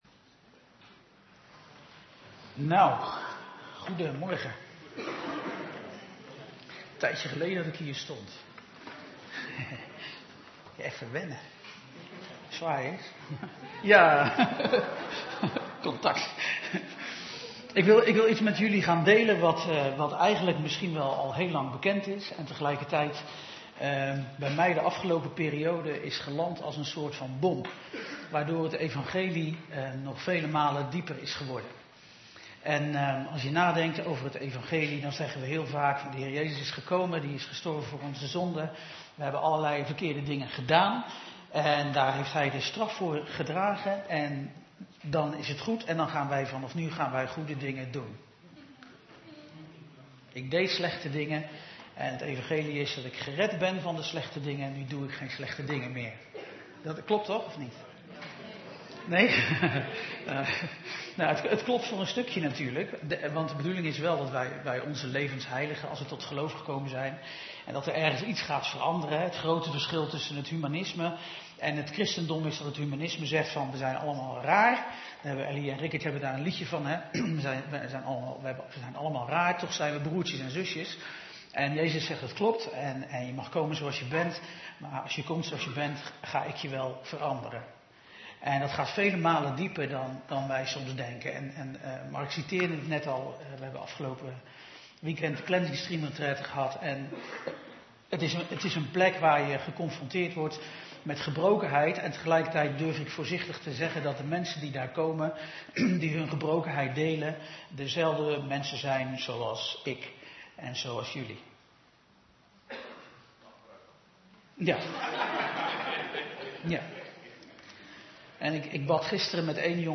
Toespraak van 15 december: de heerlijkheid van Zijn genade - De Bron Eindhoven